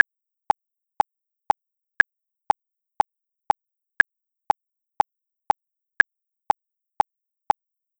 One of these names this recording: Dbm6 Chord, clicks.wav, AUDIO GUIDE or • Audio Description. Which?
clicks.wav